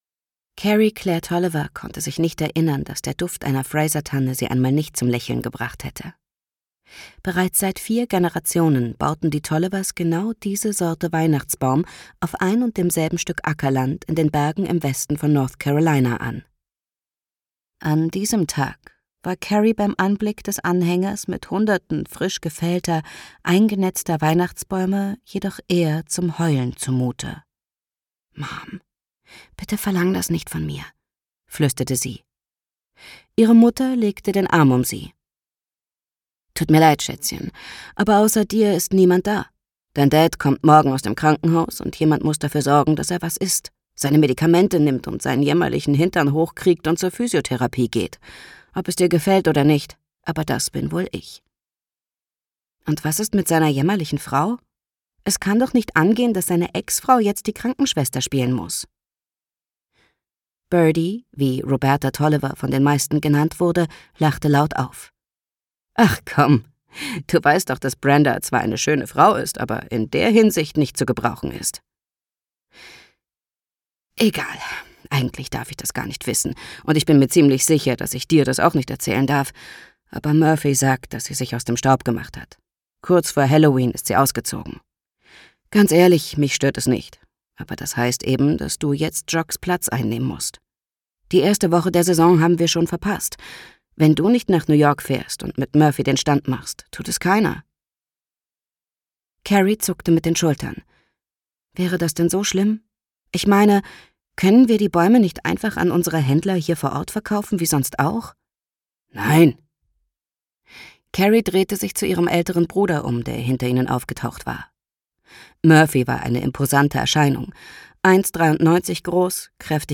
Inmitten von Weihnachtslichtern die großen Gefühle im winterlichen New York erleben mit dem neuen Hörbuch der Bestseller-Autorin Mary Kay Andrews Kerry’s